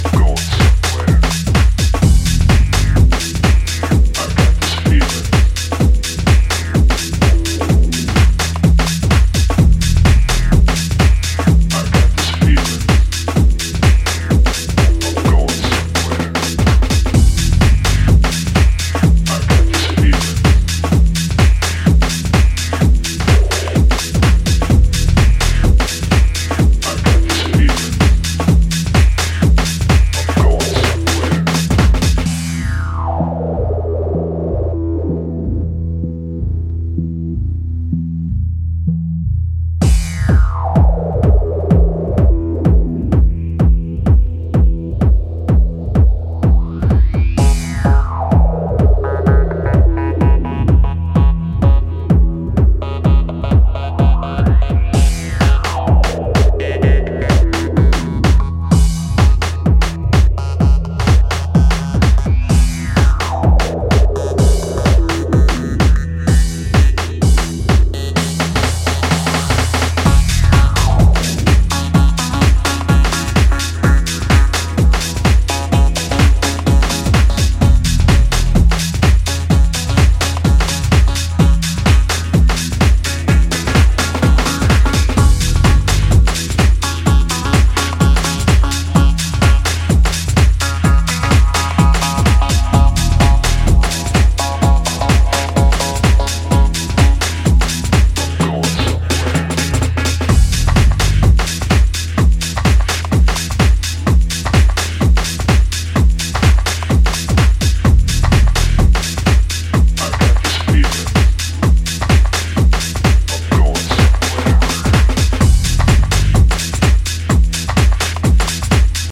本作では、ヘヴィなボトムと分厚いベースラインで爆走する、ダークでパワフルなミニマル・テック・ハウスを展開。